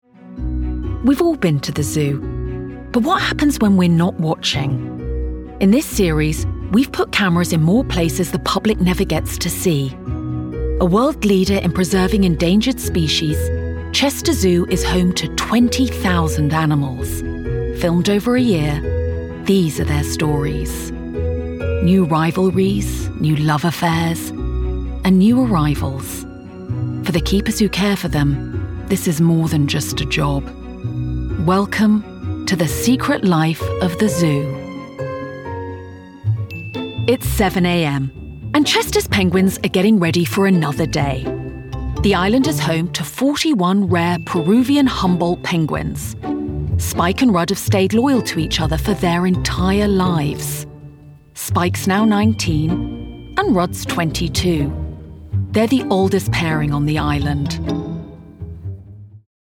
RP
Female
Assured
Bright
Dry
CHESTER ZOO DOCUMENTARY